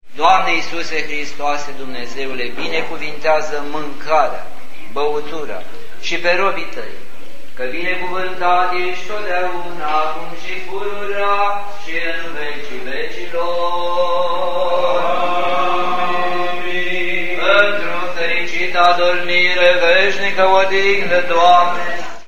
Enoriasii din Cosambesti au sarbatorit astazi hramul bisericutei din vatra satului, folosita in ultimii ani doar la slujbe de inmormantare.
Dupa slujba religioasa de la bisericuta  Adormirii Maicii Domnului, credinciosi ortodocsi au luat masa de hram la caminul cultural, unde, dupa sfintirea bucatelor, au fost serviti cu preparate din peste: